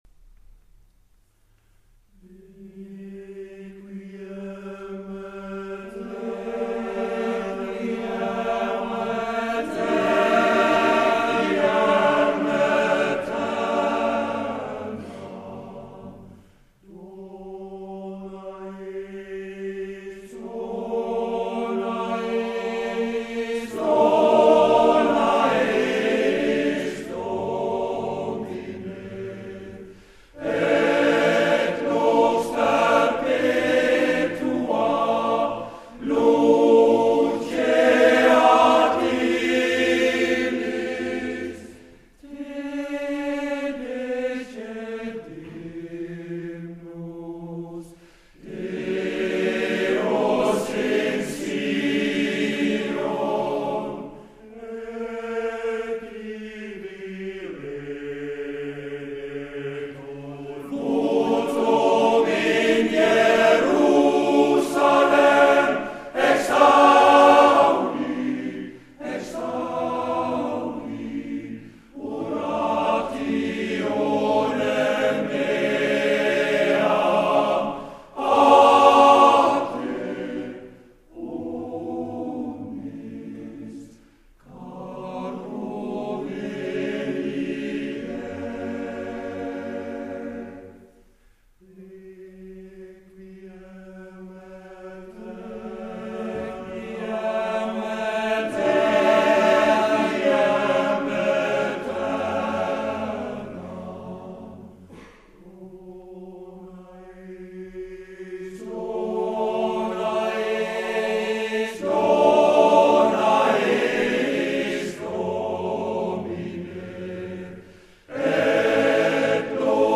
Konsertopptak
Arna Kirke